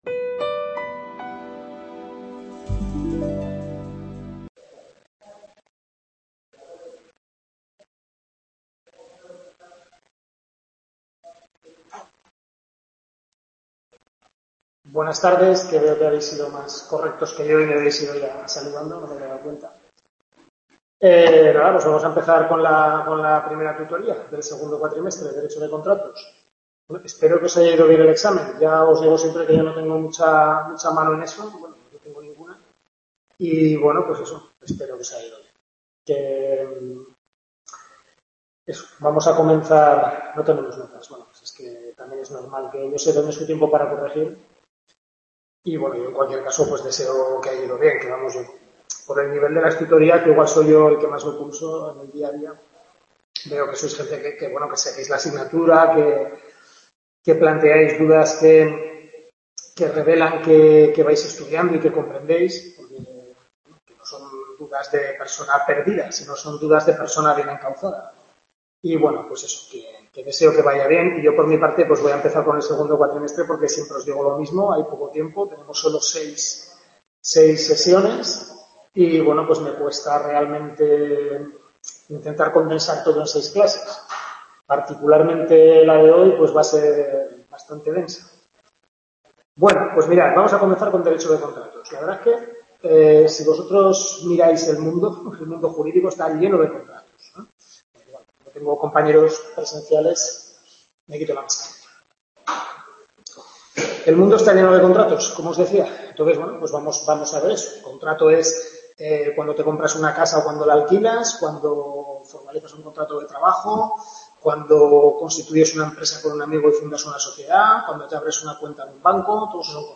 Primera tutoría de Civil II, segundo cuatrimestre